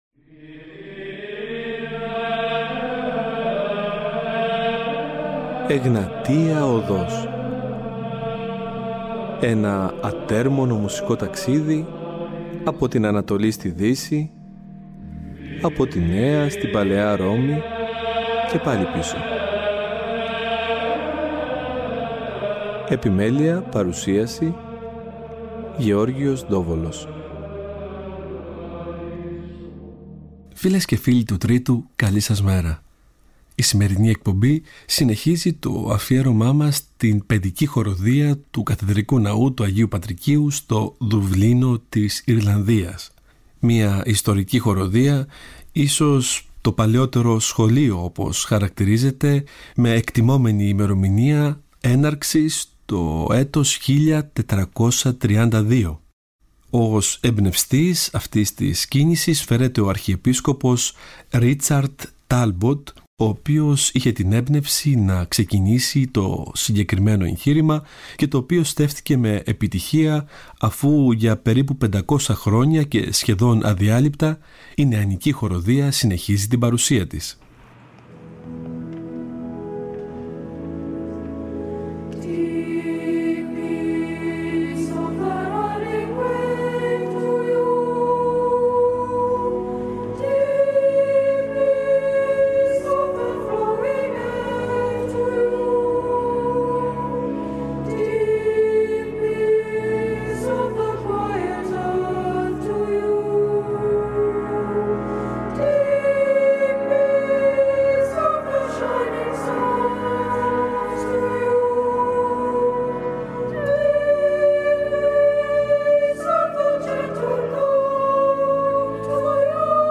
Η χορωδία συνοδεύεται με το παραδοσιακό εκκλησιαστικό όργανο , ωστόσο πολλές φορές ερμηνεύει το ρεπερτόριο της και a capella θυμίζοντας μας παλαιότερες εποχές.
Εκκλησιαστικη Μουσικη